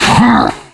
izlome_attack_hit.ogg